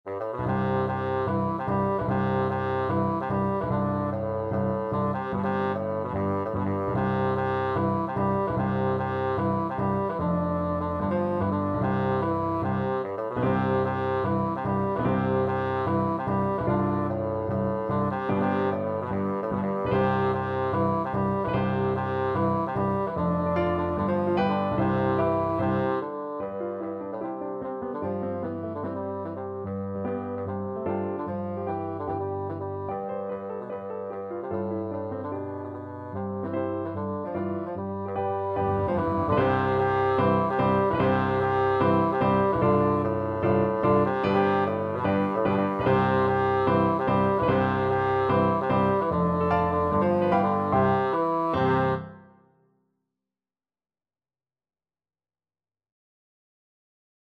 Bassoon
C major (Sounding Pitch) (View more C major Music for Bassoon )
G3-G4
Scottish